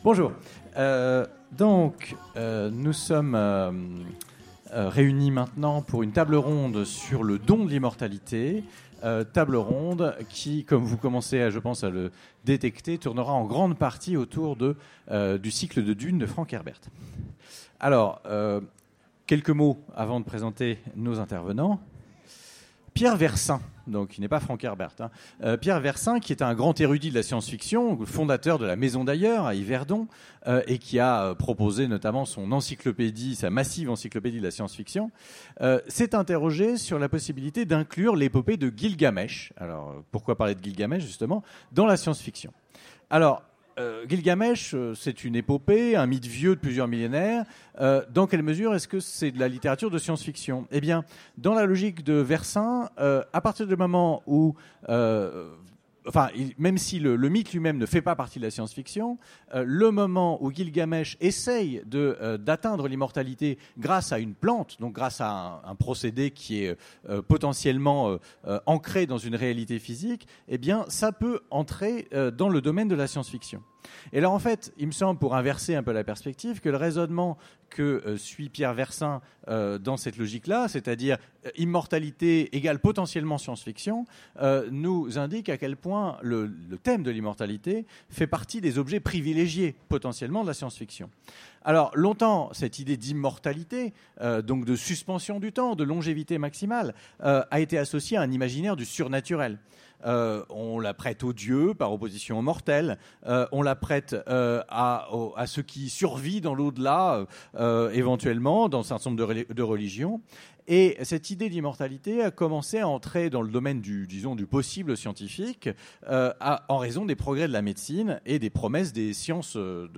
Utopiales 2017 : Conférence Le don de l’immortalité ?